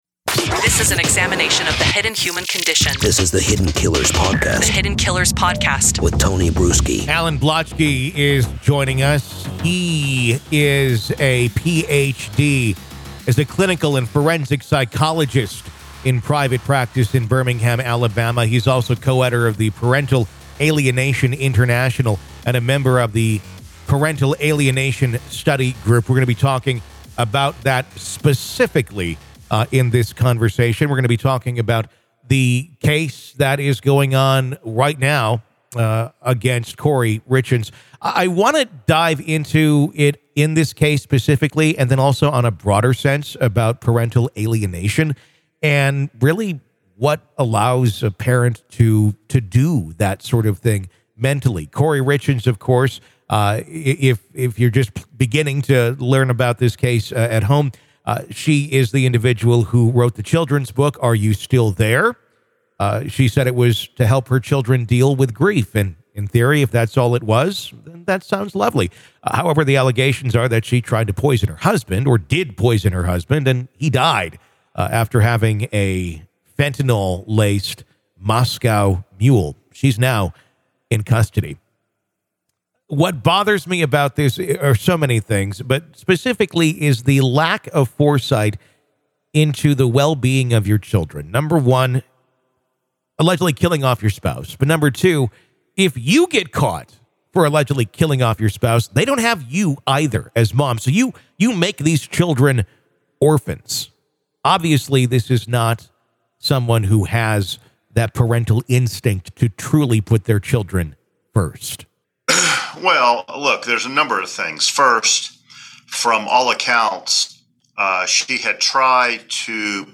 They explore the psychology of narcissism, its impact on familial relationships, and the possible repercussions for children caught in its grasp. Tune in for an enlightening discussion that goes beyond the headlines and into the heart of human behavior.